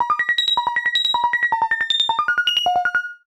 GONE GONE Arp.wav